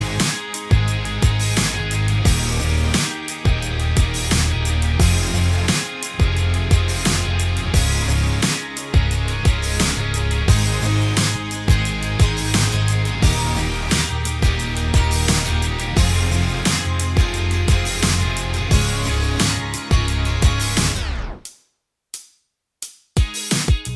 Minus Main Guitar Pop (2010s) 3:18 Buy £1.50